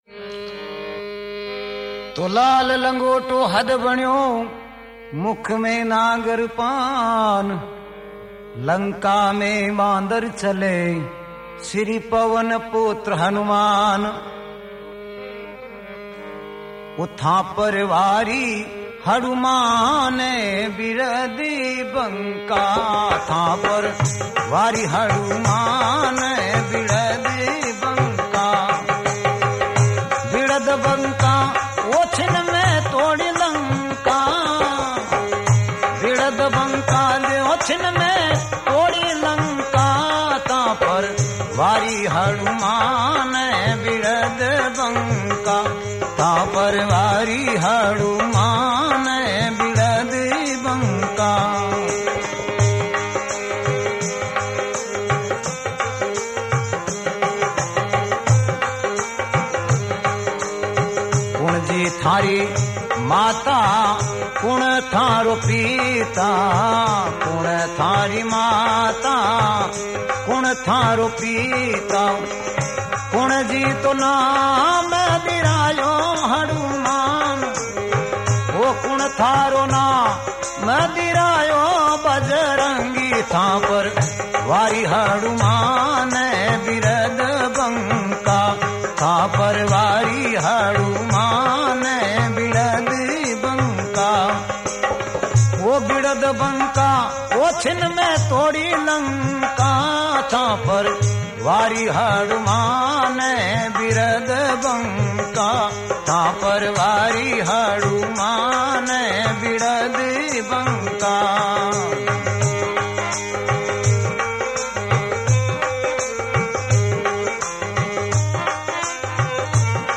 Rajasthani Songs
Hanuman Bhajan